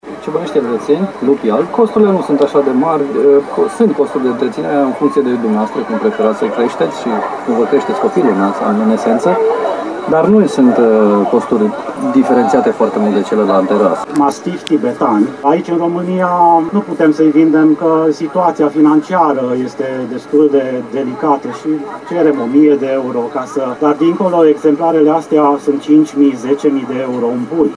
Exemplare rare și proprietari care au cheltuit chiar și zeci de mii de euro pentru a le întreține. I-am intalnit la Timișoara, unde se desfășoară unul din cele mai importante concursuri canine organizate în România.